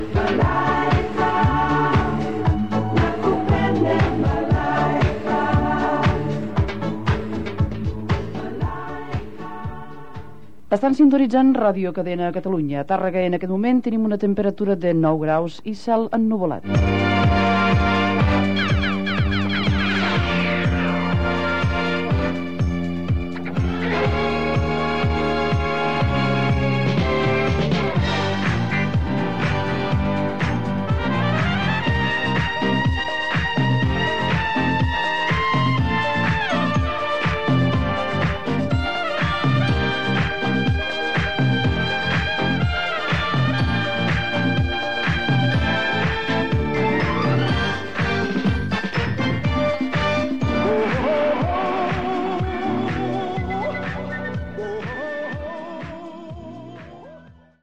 Identificació de l'emissora, temperatura i tema musical.
FM